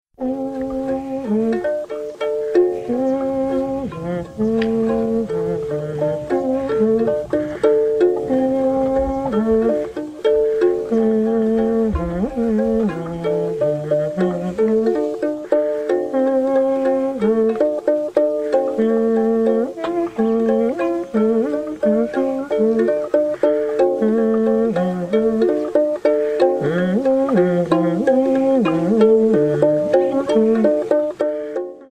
Le pluriarc
Recorded-in-Namibia-Gut-pluriarc-with-one-mans-voice.mp3